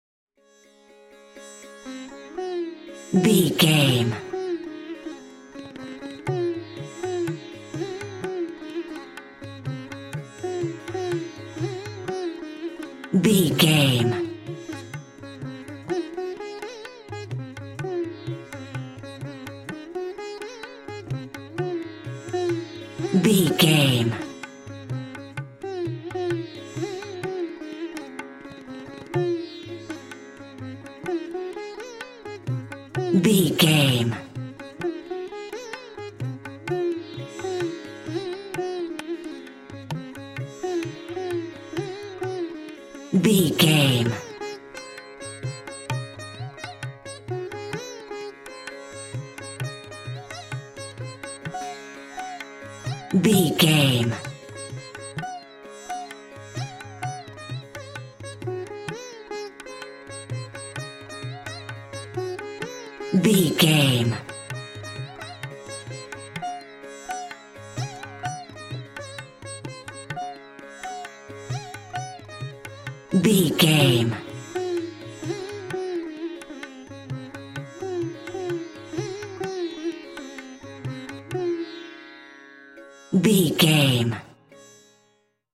Mixolydian
D♭
World Music
percussion